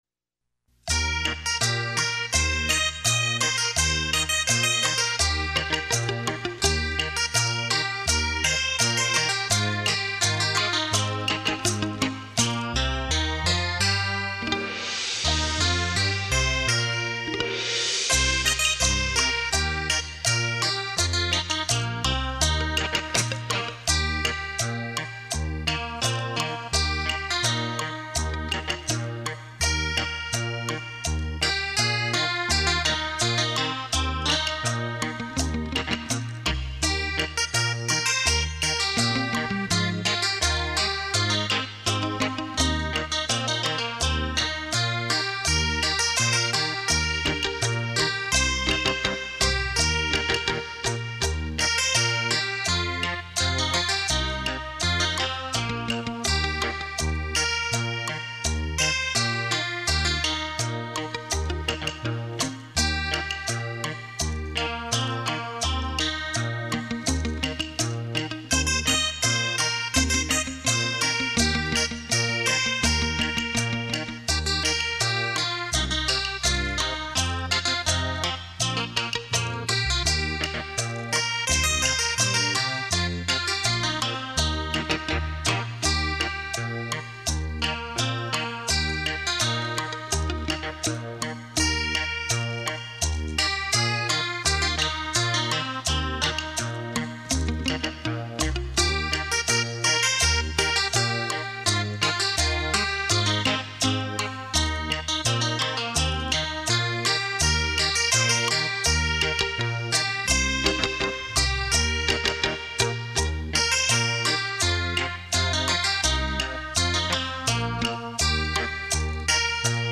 汽车音响测试碟
立体音声 环绕效果
音响测试专业DEMO碟 让您仿如置身现场的震撼感受
100%绝佳的听觉享受度 100%声历身超级震撼度 100%立体声雷射音效